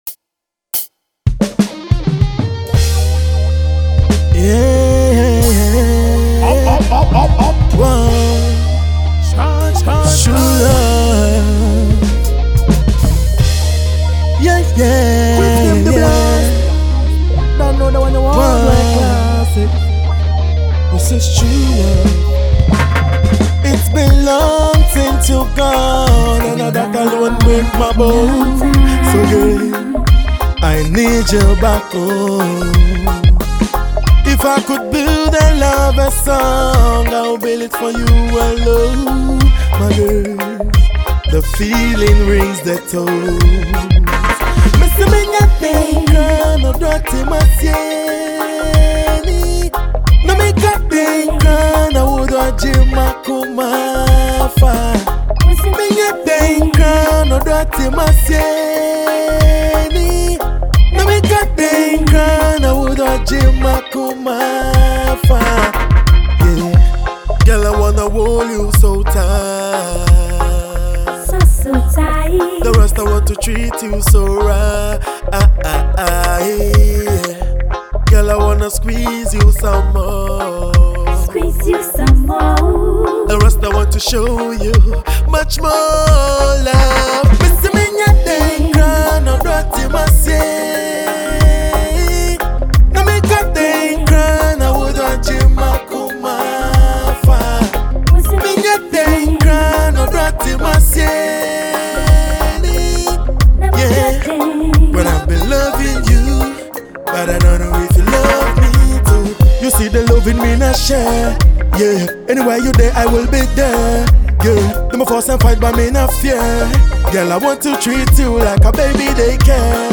Ghanaian Reggae/Dancehall act